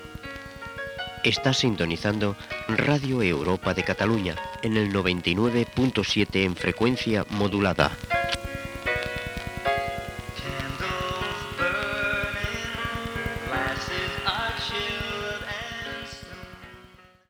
bc3bf23a782f9b337469a1463ab943108caa2cb8.mp3 Títol Radio Europa de Cataluña Emissora Radio Europa de Cataluña Titularitat Tercer sector Tercer sector Comercial Descripció Identificació i música.